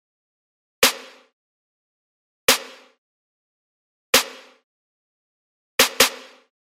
描述：陷阱和科幻的结合。沉重的打击和神秘感。
Tag: 145 bpm Trap Loops Percussion Loops 1.11 MB wav Key : D